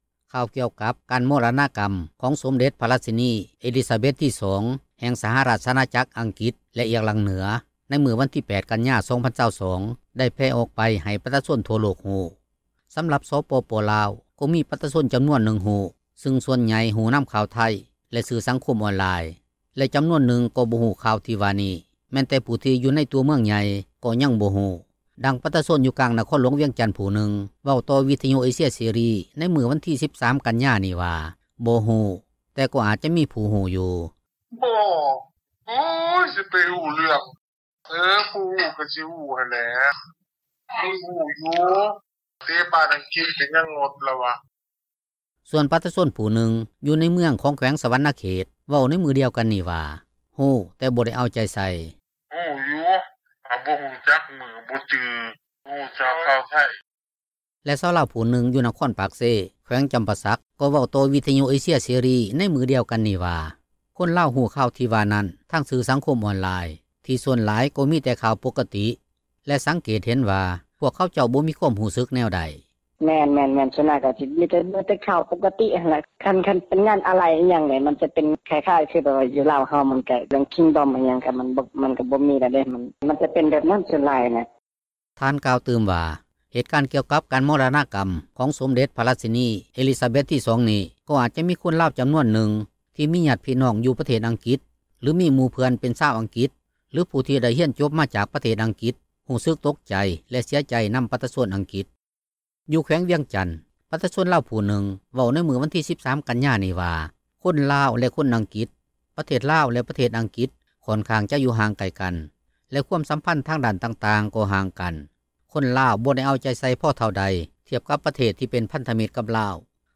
ດັ່ງປະຊາຊົນ ຢູ່ນະຄອນຫລວງວຽງຈັນ ຜູ້ນຶ່ງເວົ້າ ຕໍ່ວິທຍຸເອເຊັຽເສຣີ ໃນມື້ວັນທີ 13 ກັນຍານີ້ວ່າ ບໍ່ຮູ້ ແຕ່ກໍອາຈມີຜູ້ຮູ້ຢູ່.
ສ່ວນປະຊາຊົນຜູ້ນຶ່ງ ຢູ່ໃນເມືອງ ຂອງແຂວງສວັນນະເຂດ ເວົ້າໃນມື້ດຽວກັນນີ້ວ່າ ຮູ້ແຕ່ບໍ່ໄດ້ເອົາໃຈໃສ່.